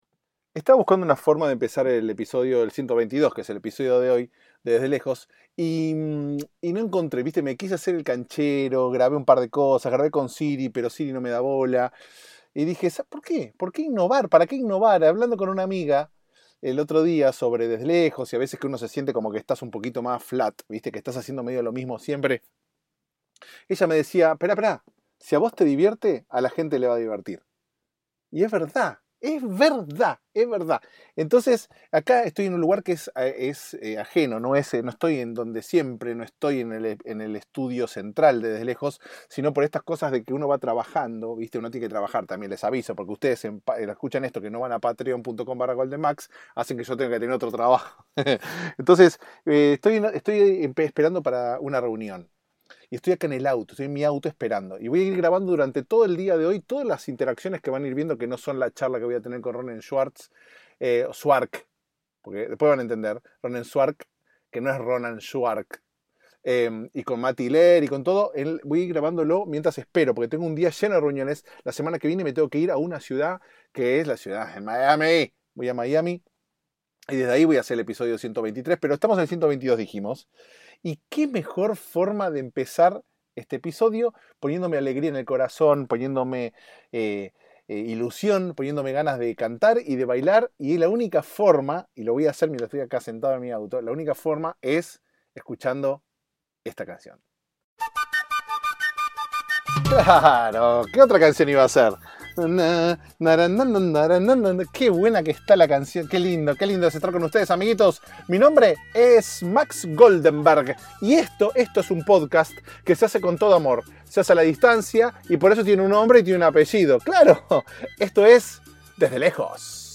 Grabado íntegramente en exteriores (?)